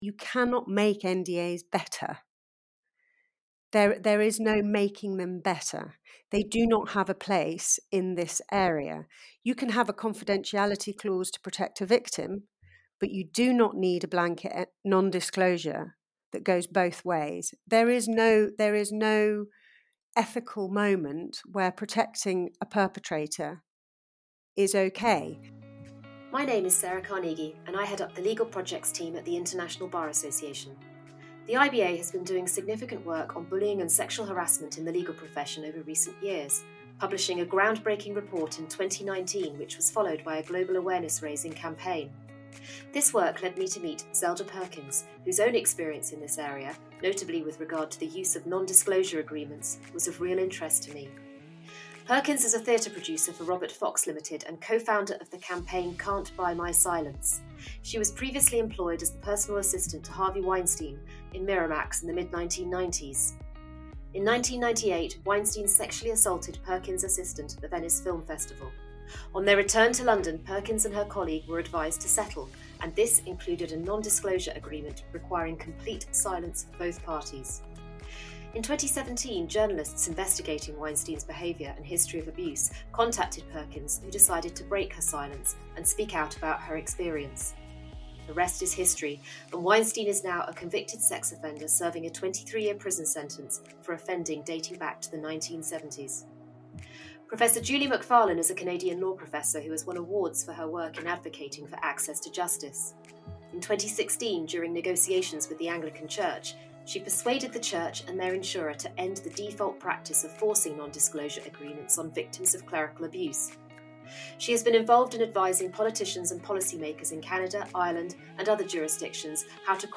Sustainable law in action: Non-disclosure agreements – an interview